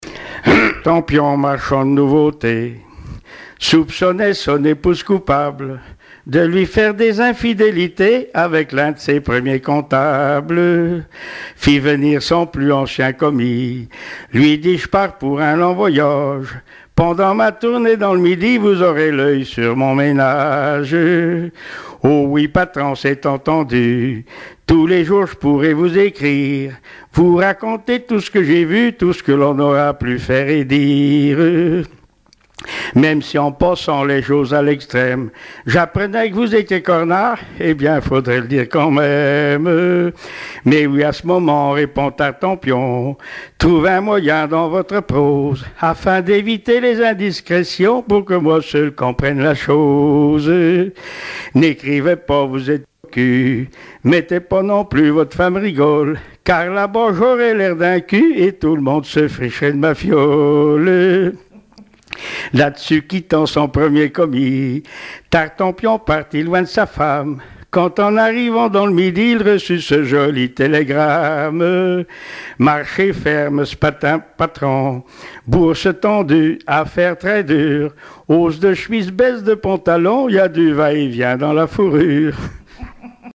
Genre strophique
Témoignages et chansons traditionnelles et populaires
Pièce musicale inédite